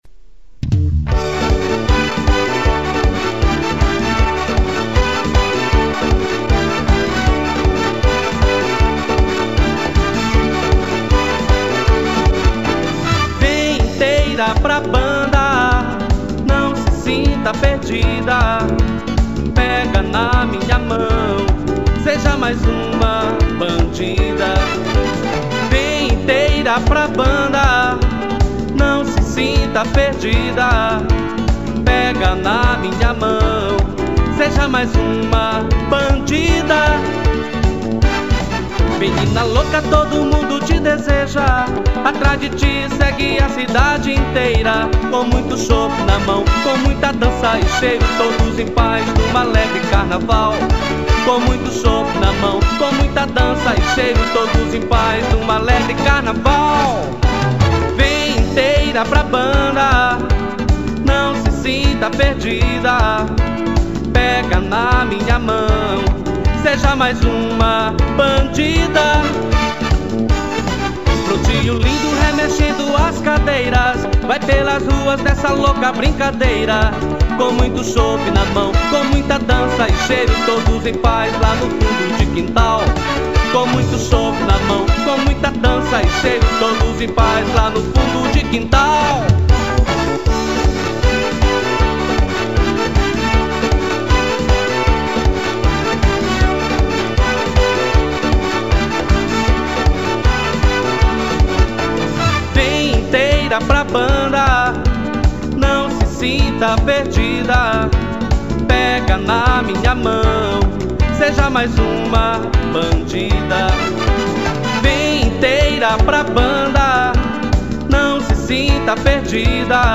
Machinha